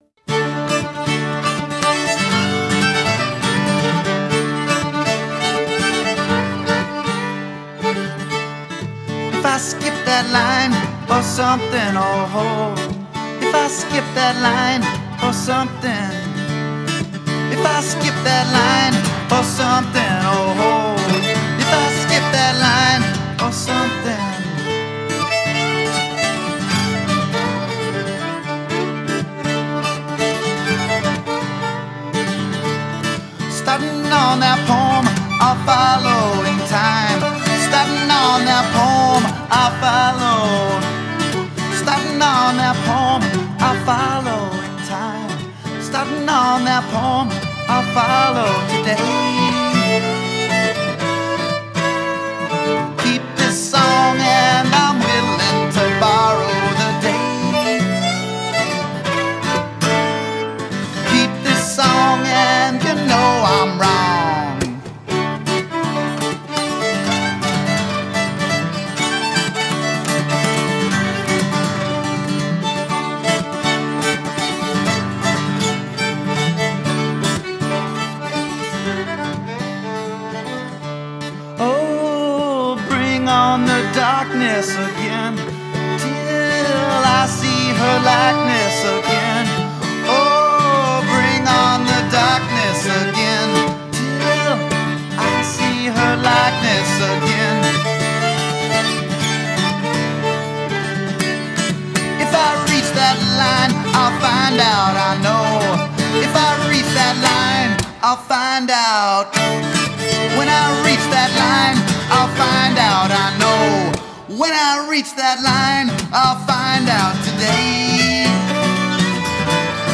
Guitar and vocals
Mandolin and fiddle